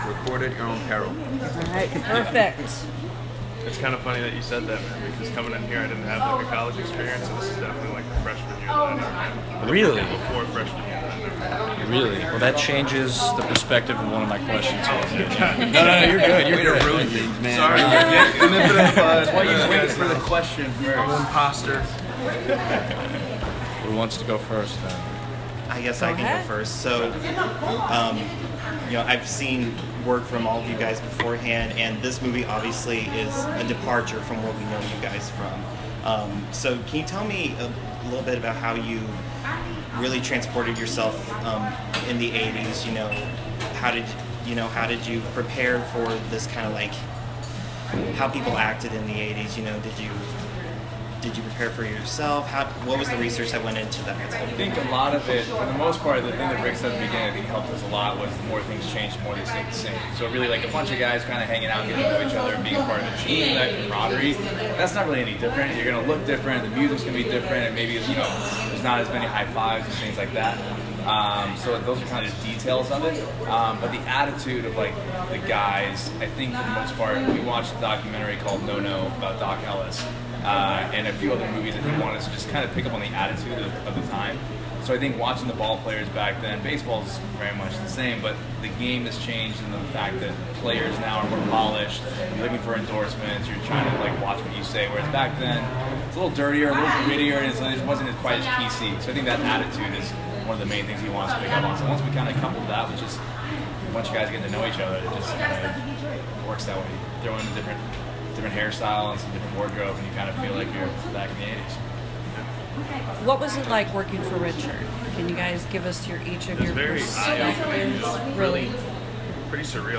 ROUND TABLE INTERVIEW with BLAKE JENNER, RYAN GUZMAN and TYLER HOECHLIN of EVERYBODY WANTS SOME
everybody-wants-some-interview.m4a